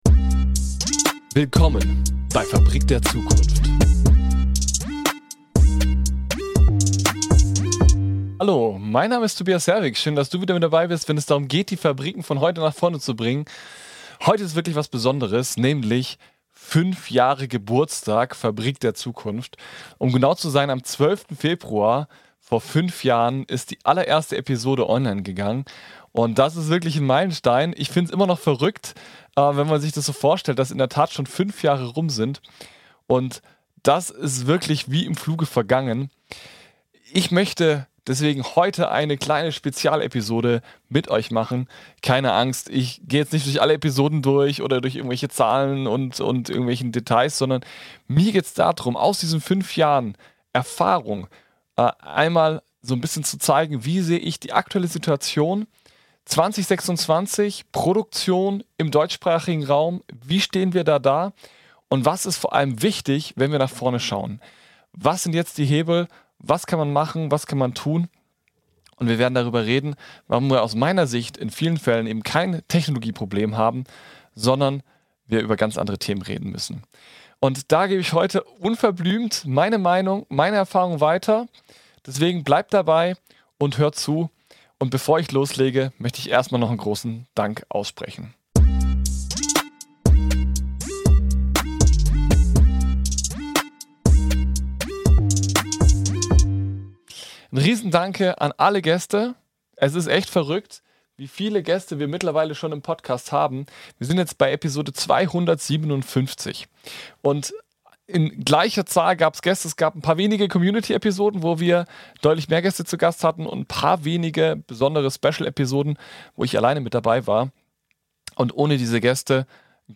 Ohne Podcast-Gast